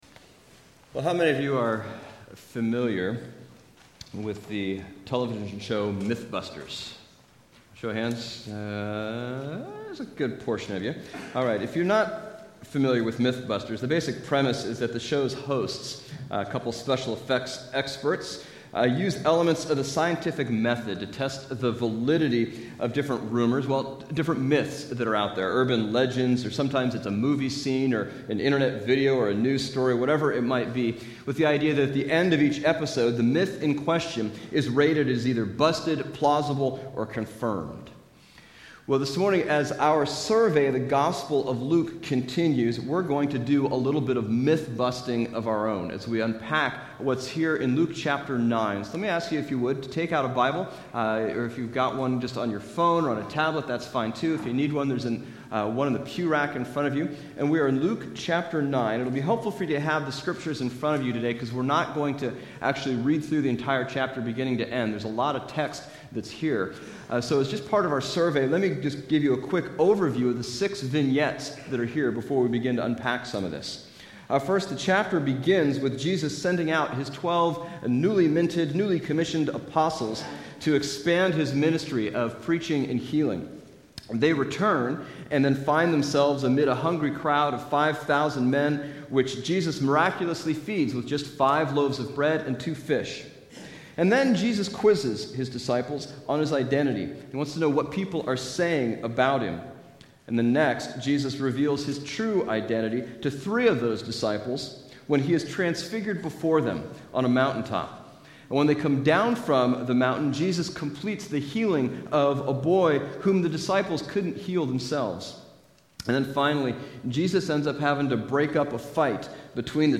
Bible Text: Luke 9:1-50 | Preacher